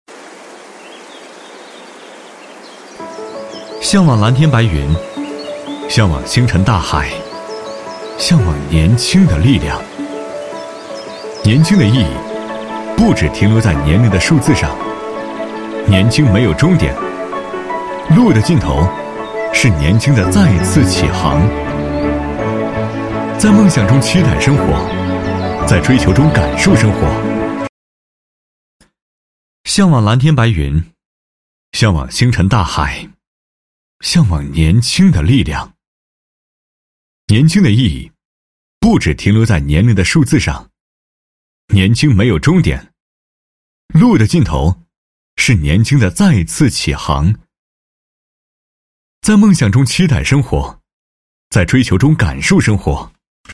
Narración
Clean audio with no breaths or mouth noises
Sennheiser MKH 416 Mic, UA Volt 276 Interface, Pro Recording Booth, Reaper
BarítonoBajo